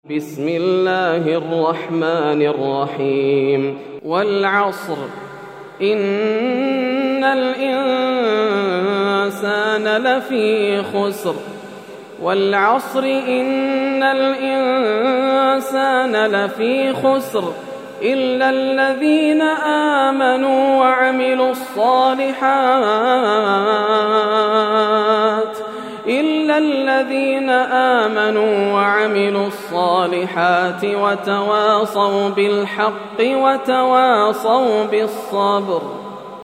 سورة العصر > السور المكتملة > رمضان 1431هـ > التراويح - تلاوات ياسر الدوسري